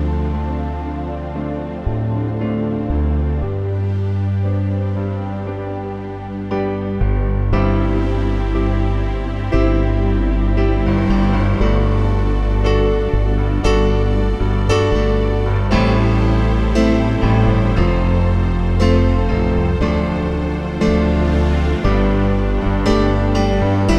One Semitone Down Pop (1980s) 3:21 Buy £1.50